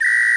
animals
cricket1.mp3